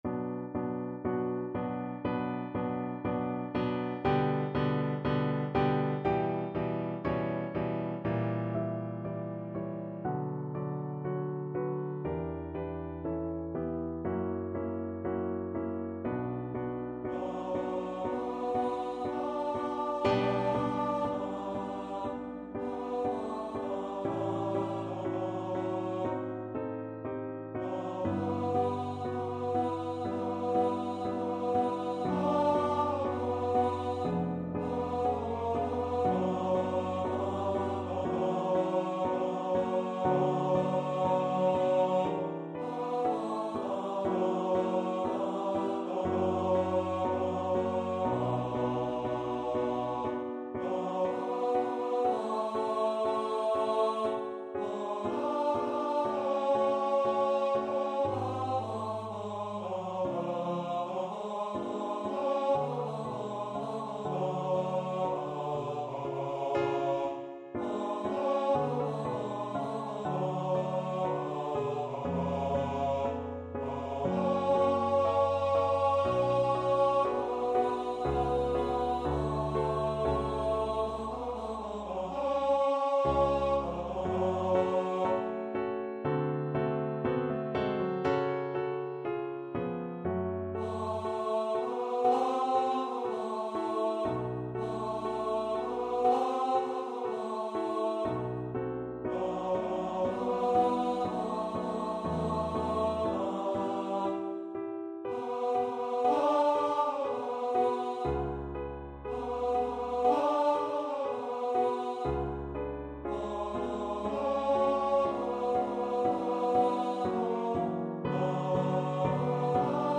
Bass Voice
4/4 (View more 4/4 Music)
Adagio
B minor (Sounding Pitch) (View more B minor Music for Bass Voice )
Classical (View more Classical Bass Voice Music)